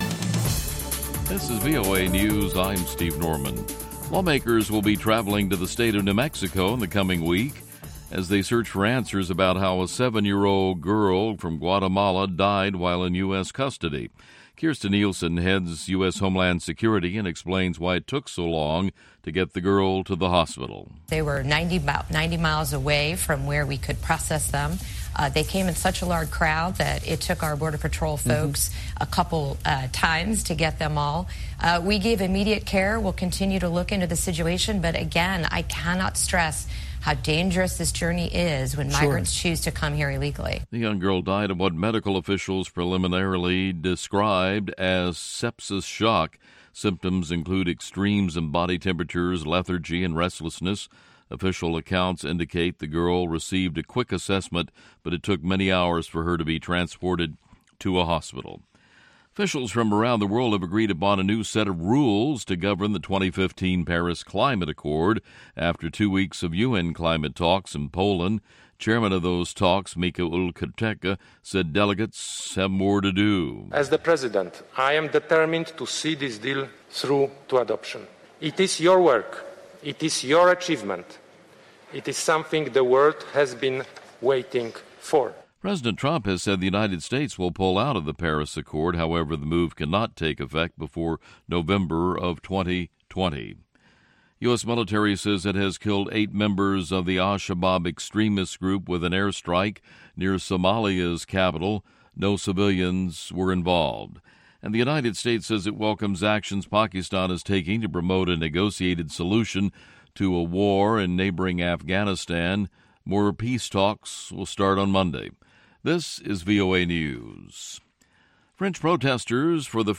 Enjoy the first half of this edition with samples of Africa street music: mchiriku (Tanzania), township pop and kwaito (South Africa), babatone (Malawi), plus other, more polished Afrobeat and Afropop. Today's second half features a live studio session with Tiharea. This acapella trio of female cousins from Madagascar – is amazing! The interview is in French and English.